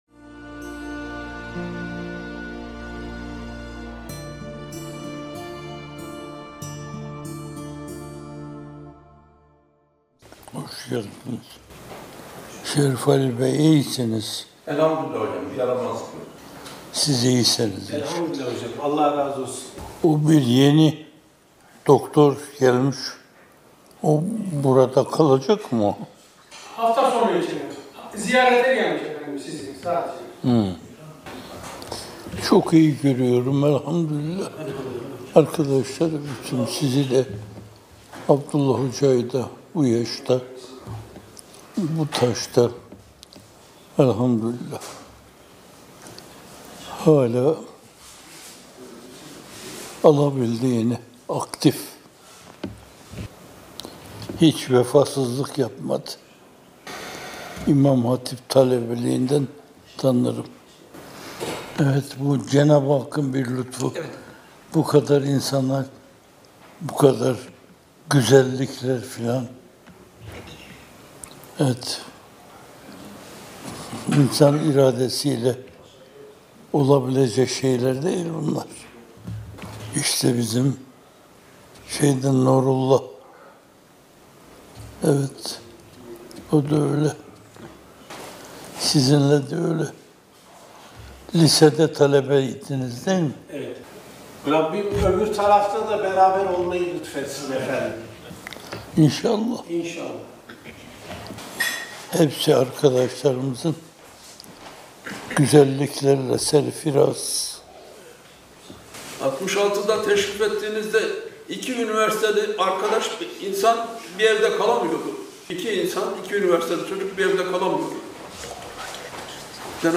Muhterem Fethullah Gülen Hocaefendi’nin, hizmet gönüllülerinden oluşan bir grup ve kadim dostlarıyla 5 Ekim 2024 tarihinde gerçekleştirdiği ve ilk kez yayınlanan hasbihalini sizlerle paylaşıyoruz.